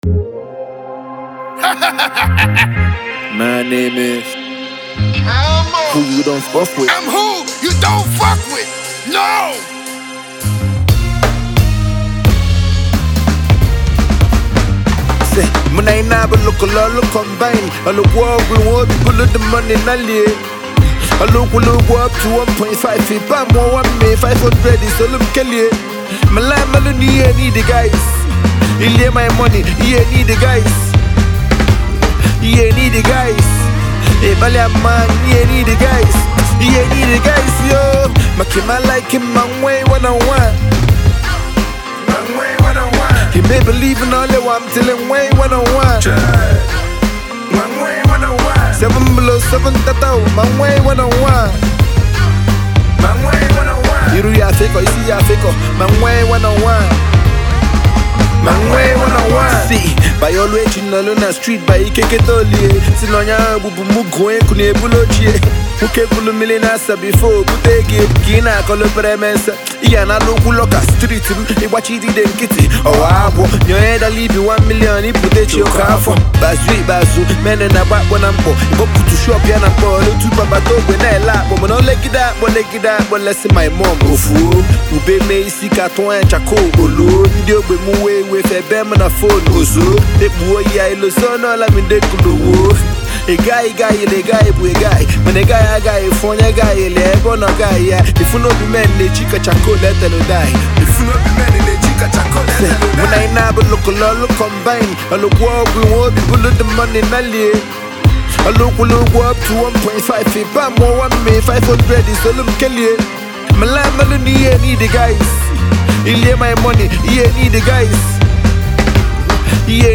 Indigenous rapper